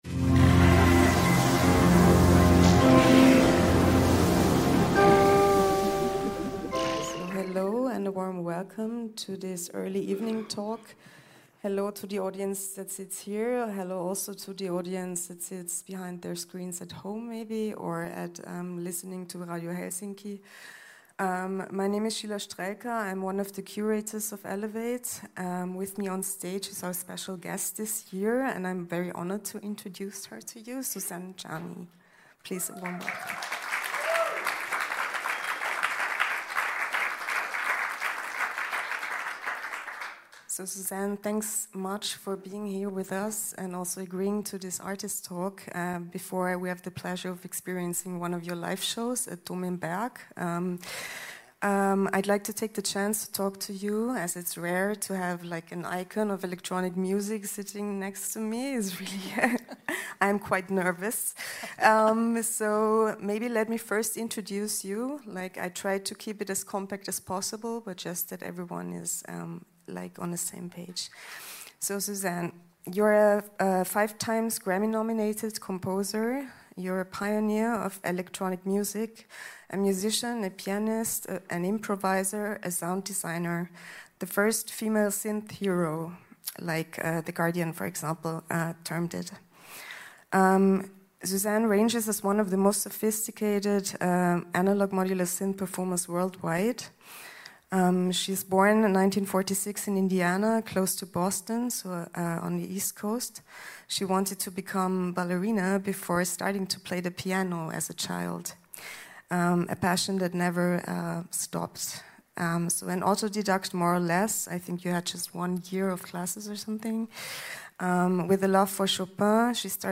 Im Gespräch blicken wir auf ihre bahnbrechende Karriere und ihre wegweisenden Beiträge zur elektronischen Musik.